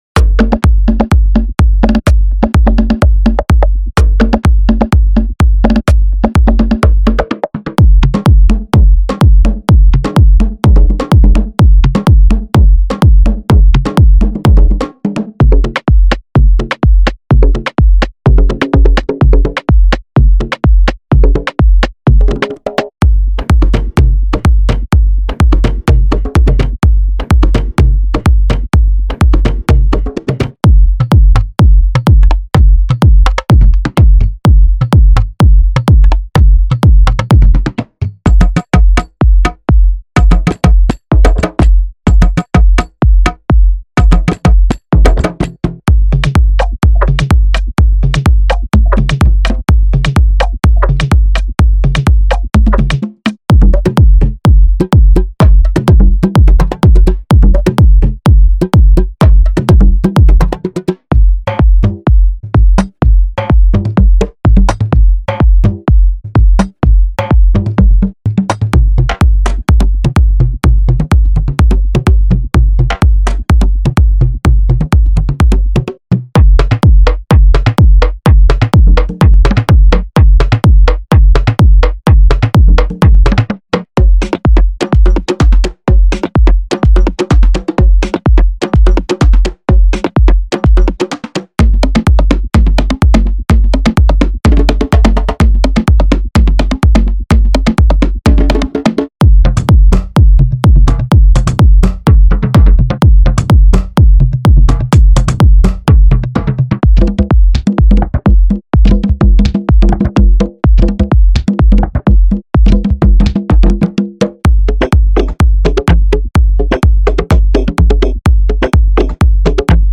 Genre:House
手作業で録音されたパーカッションを現代的なデジタルプロセッシングでブレンドし、最大のインパクトを実現しています。
ご注意：オーディオデモはラウドで圧縮された均一な音に加工されています。
100 percussion drum loops (kick-free)
302 one shots (congas,toms, misc percs)
125-128 bpm